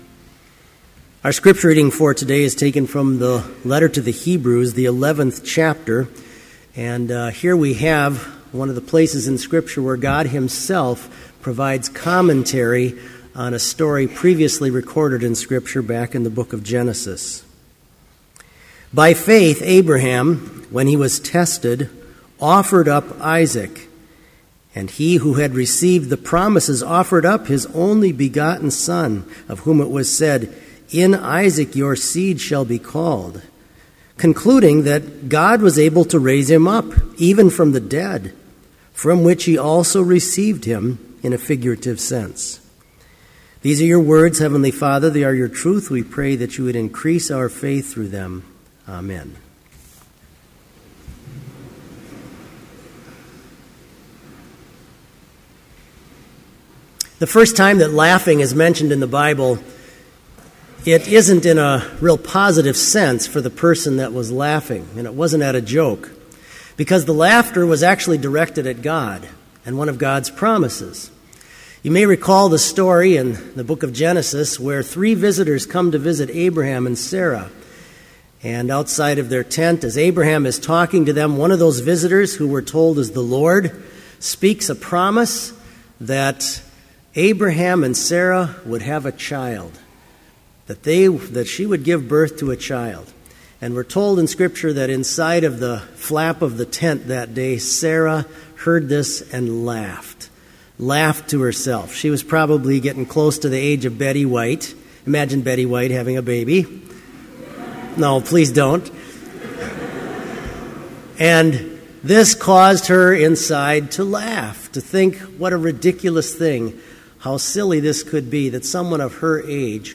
Complete Service
This Chapel Service was held in Trinity Chapel at Bethany Lutheran College on Monday, September 16, 2013, at 10 a.m. Page and hymn numbers are from the Evangelical Lutheran Hymnary.